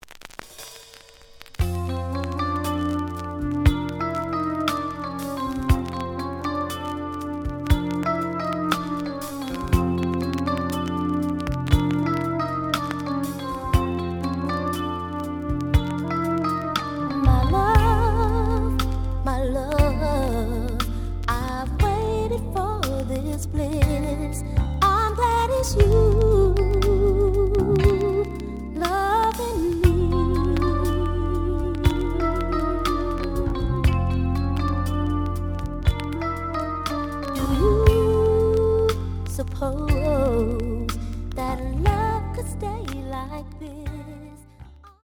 The audio sample is recorded from the actual item.
●Genre: Soul, 80's / 90's Soul